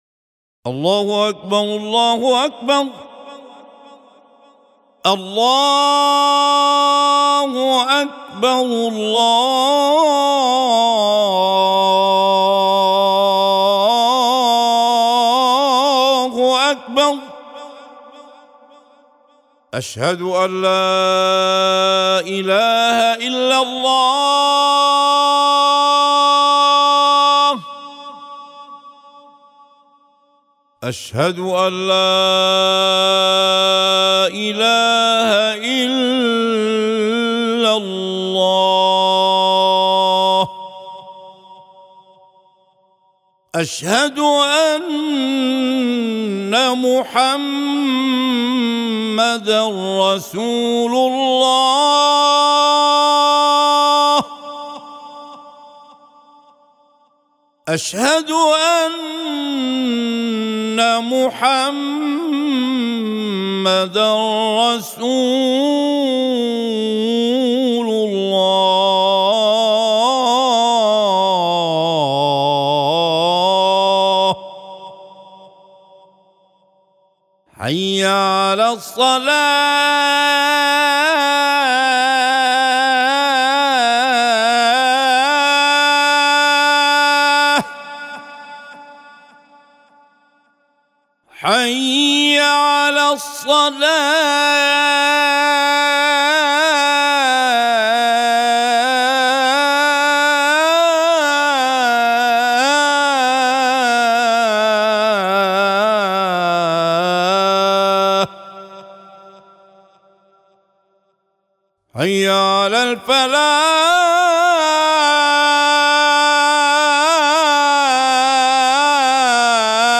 Adhan 35.mp3